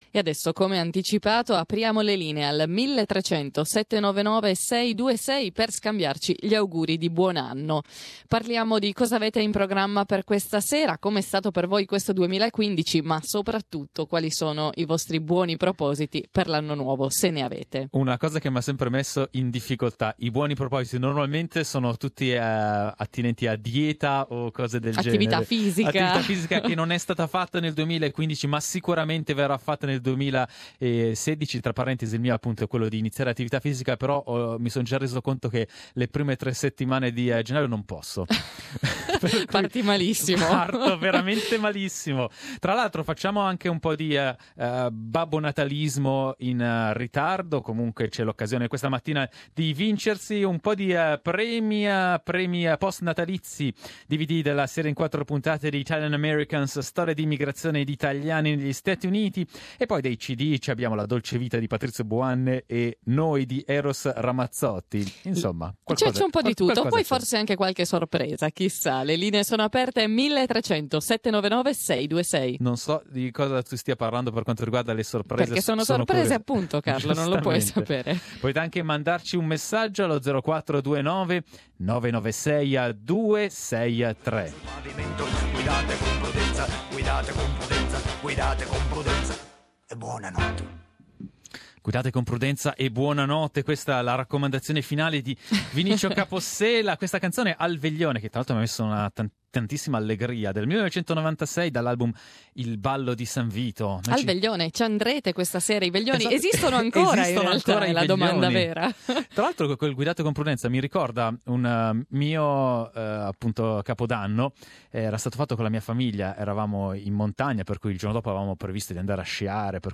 Here's our last talkback for 2015.